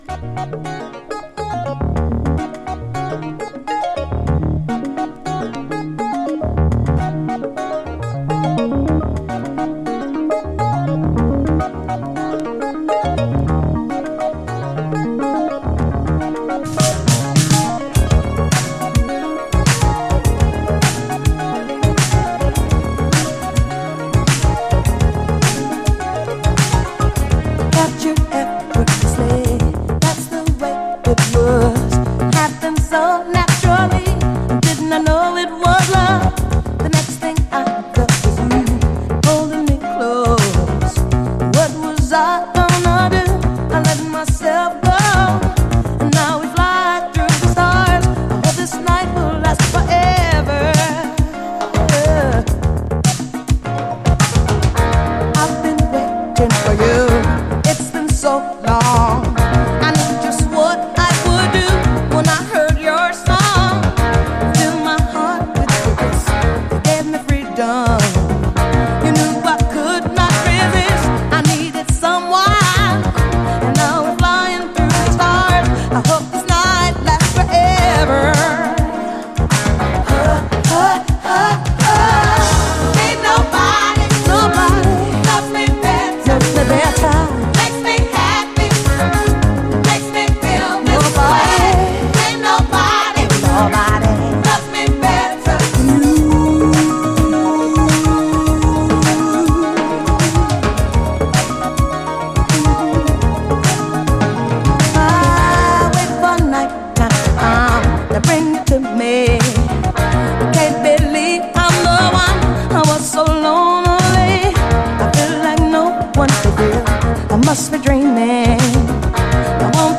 SOUL, 70's～ SOUL, DISCO
様々なジャンルでカヴァー、サンプリングされる80’Sディスコ・クラシック！唯一無二の強烈な存在感、カッコいい曲です。